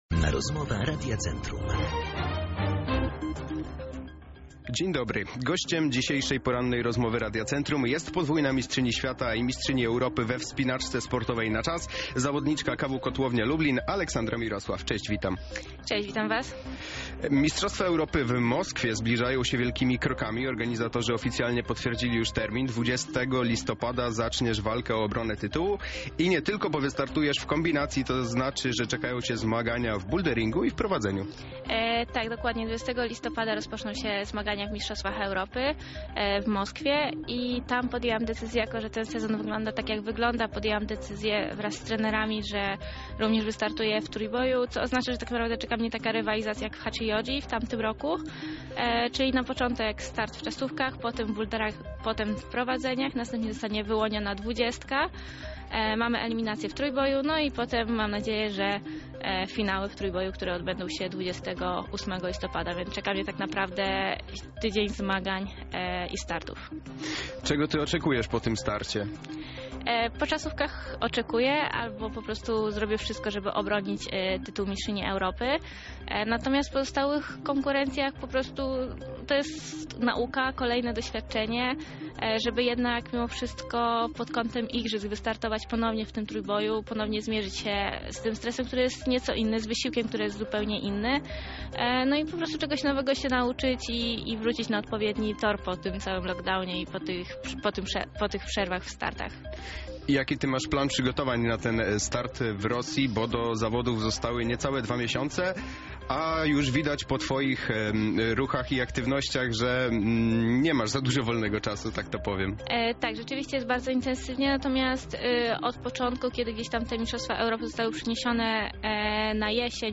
Aleksandra Mirosław, która była dzisiaj gościem Porannej Rozmowy Radia Centrum, mówi o tym, z jakim nastawieniem pojedzie na zawody do Rosji.